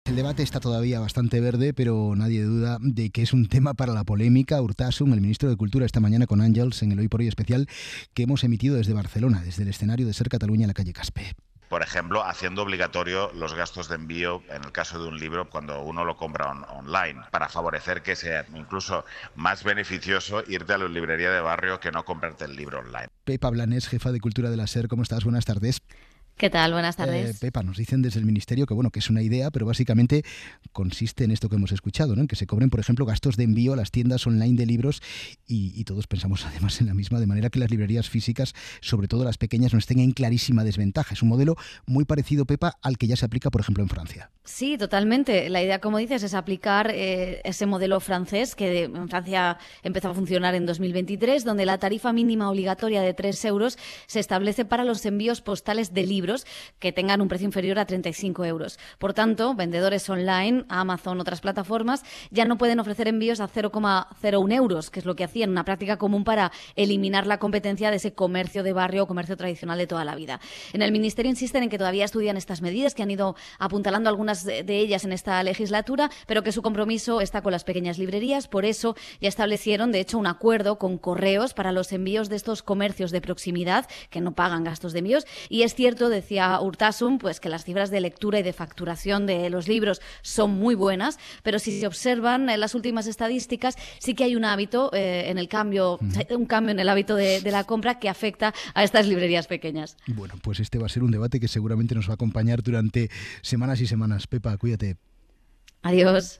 'Hora 14' es el informativo líder del mediodía.